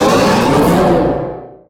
Cri de Méga-Élecsprint dans Pokémon HOME.
Cri_0310_Méga_HOME.ogg